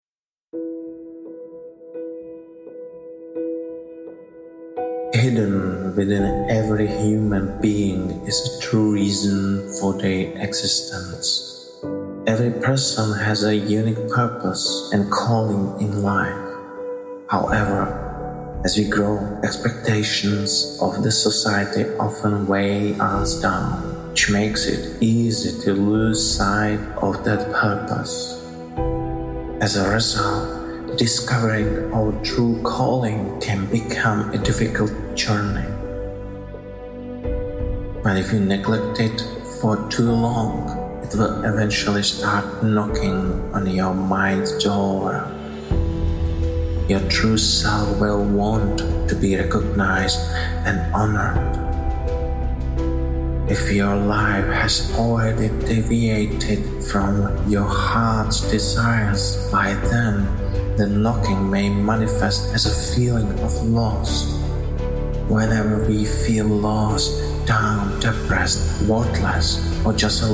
motivational speech